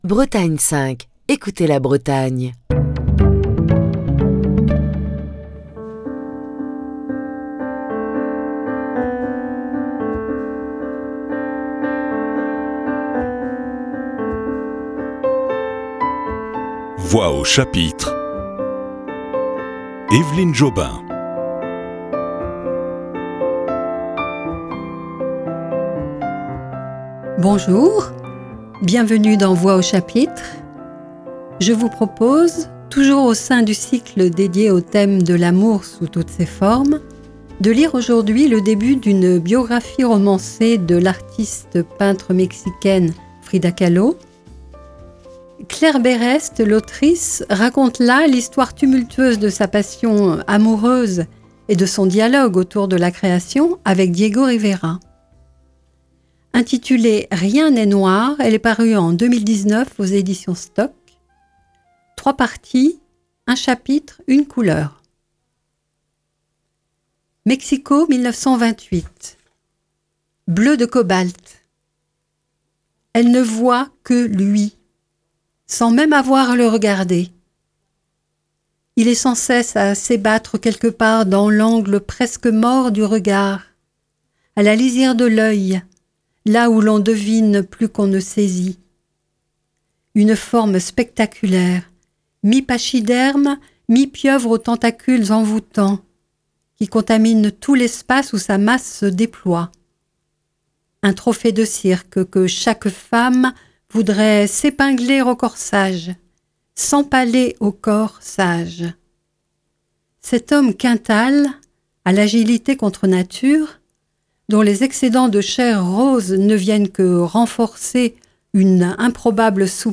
lit aujourd'hui une biographie romancée de l'artiste peintre mexicaine Frida Kahlo. Le texte est extrait du livre de Claire Berest, Rien n'est noir, paru chez Stock en 2019.